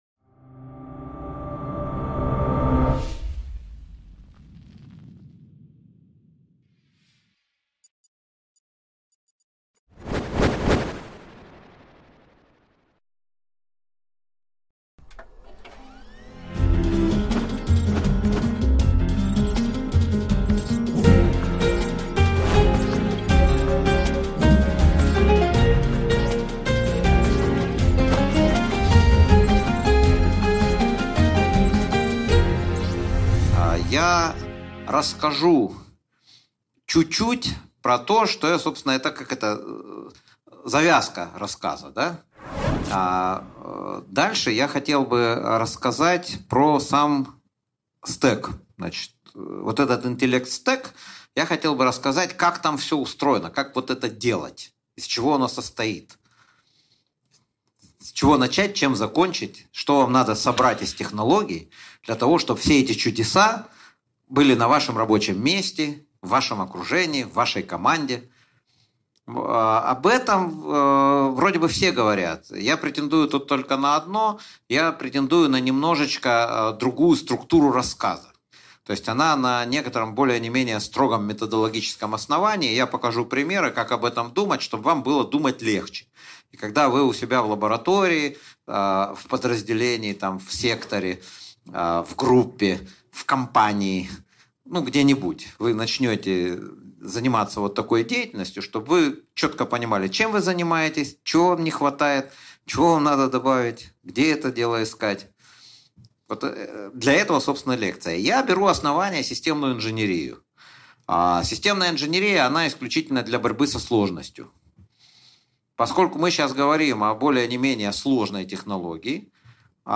Аудиокнига Интеллект-стек, ч. III | Библиотека аудиокниг